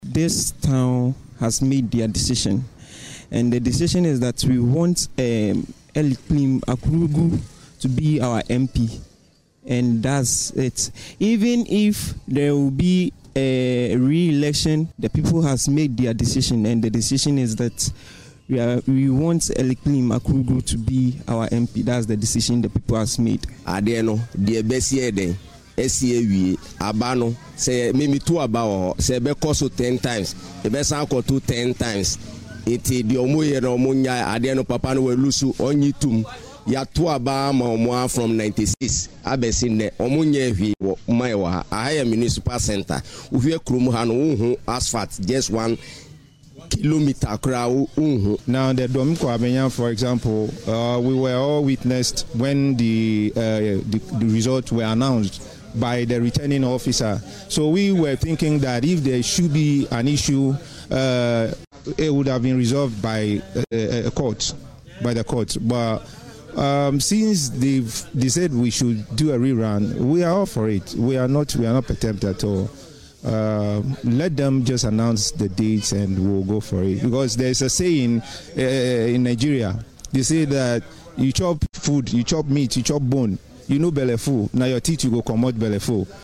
In conversations with residents, many expressed their determination to participate in the rerun and voiced strong support for their preferred candidates.